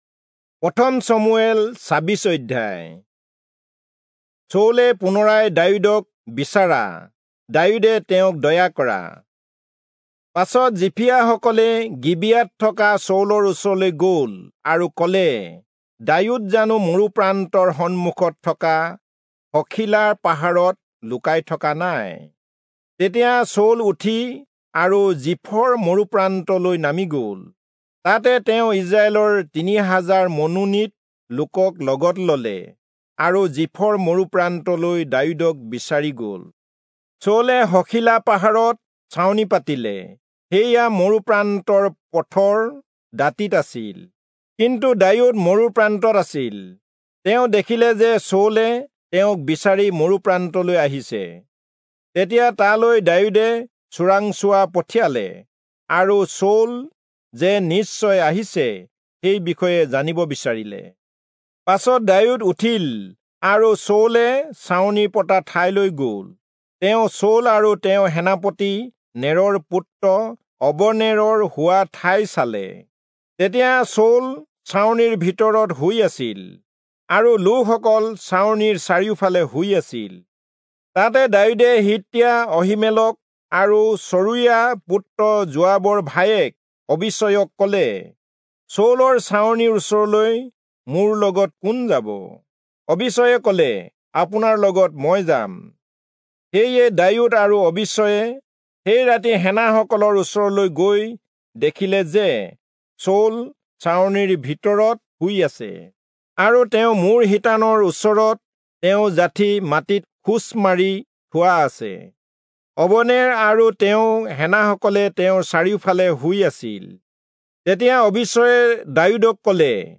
Assamese Audio Bible - 1-Samuel 10 in Tev bible version